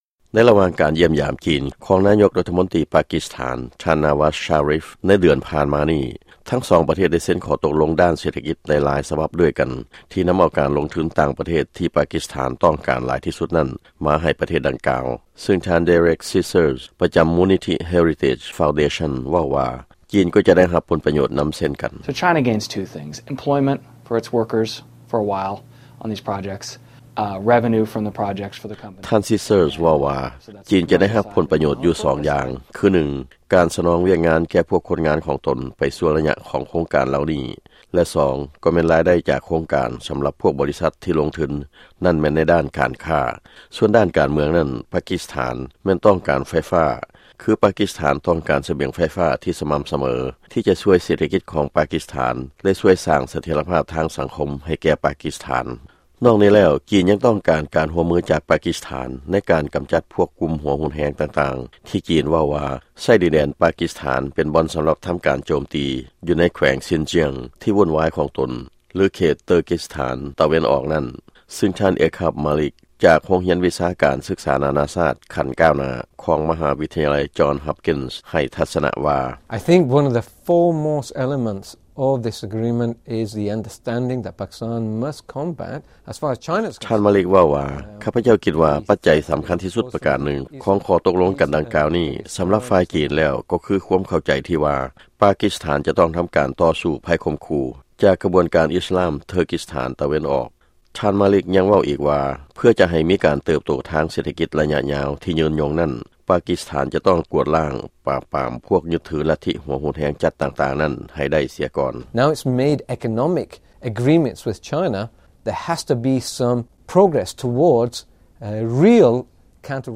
ຟັງລາຍງານ ຈີນ ລົງທຶນໃນປາກີສຖານ